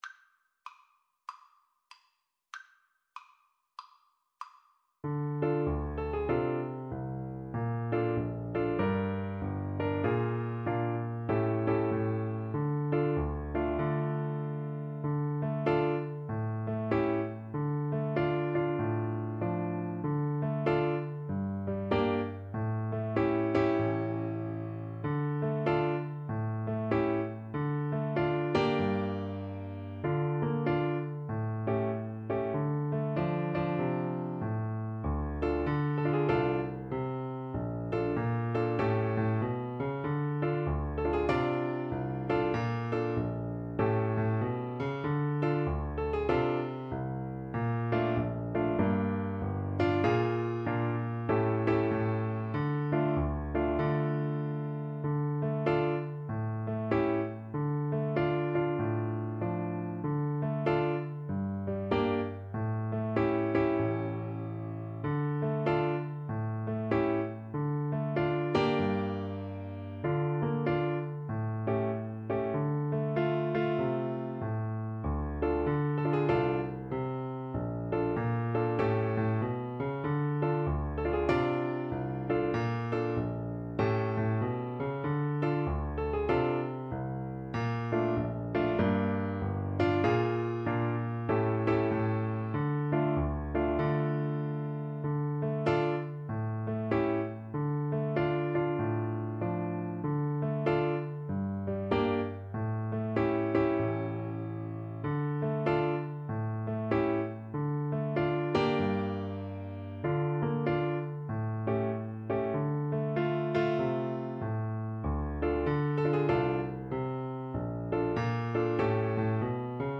This famous Blues melody has its origins in the 18th century English folk ballad The Unfortunate Rake.
D minor (Sounding Pitch) (View more D minor Music for Piano Duet )
4/4 (View more 4/4 Music)
Medium Swing = 96
Piano Duet  (View more Easy Piano Duet Music)
jazz (View more jazz Piano Duet Music)